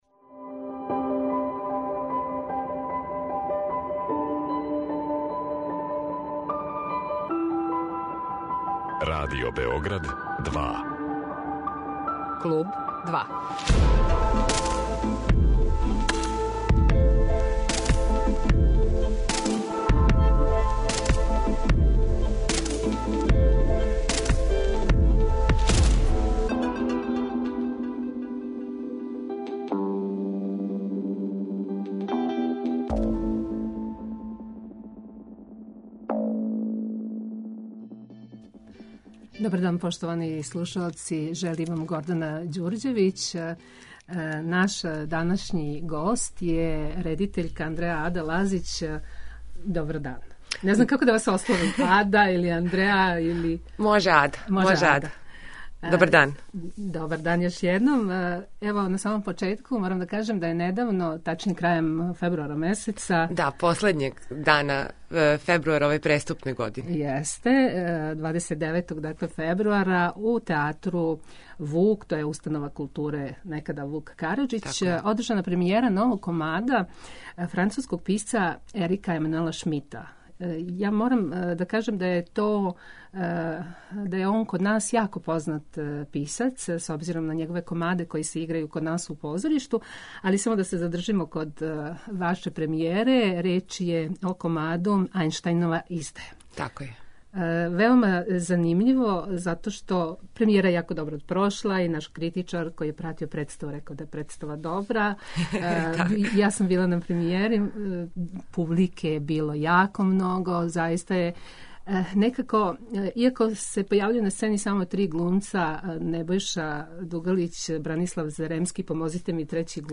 Гост је редитељка